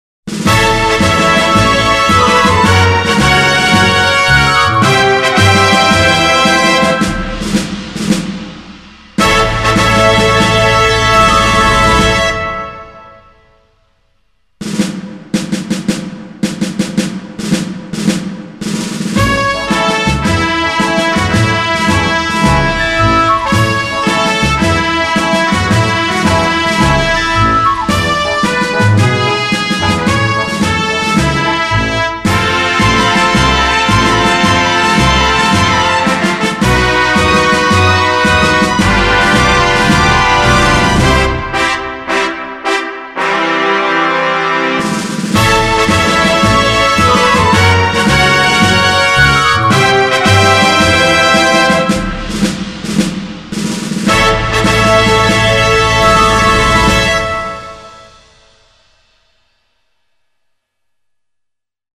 Нет но вот насчет линейного входа заметил следующее: глядя на записанный достаточно громко сигнал виден клиппинг на определенном уровне(точное число не смотрел но примерно -6...-3дб), все что выше обрезано.